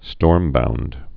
(stôrmbound)